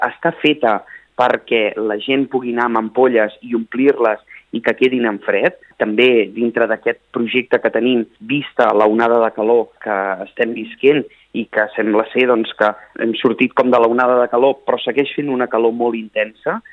Josep Grima és el tinent d’Alcaldia de Serveis públics: